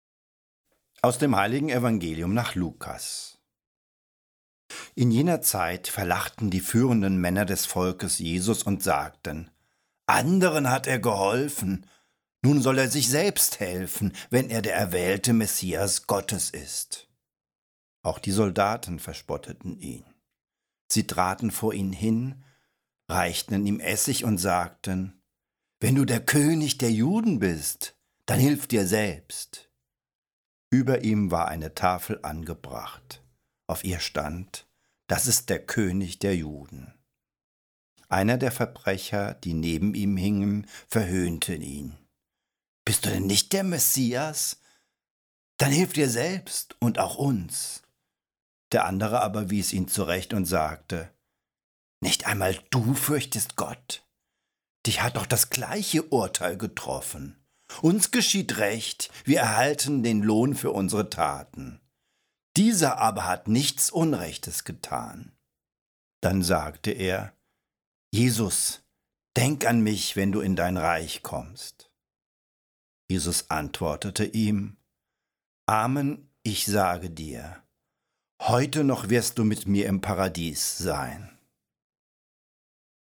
Lesepredigt Bistum Würzburg
Christkoenigssonntag-2022-Evangelium-final.mp3